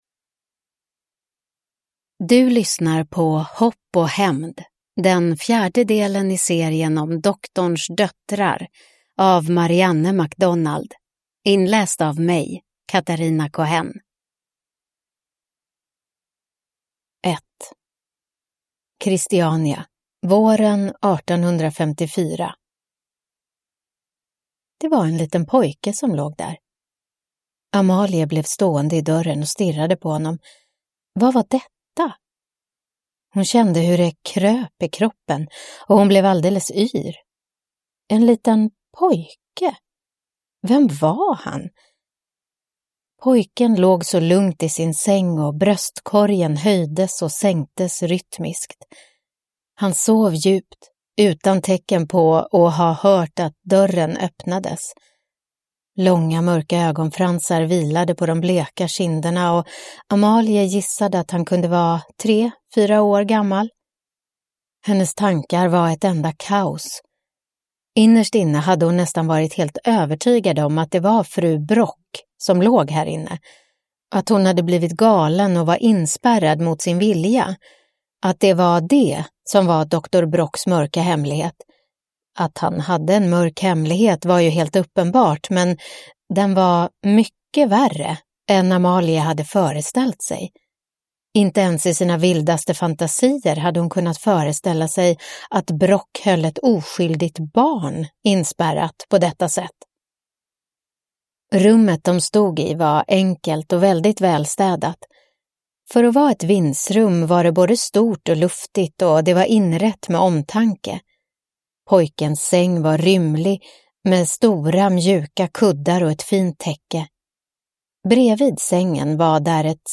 Hopp och hämnd (ljudbok) av Marianne MacDonald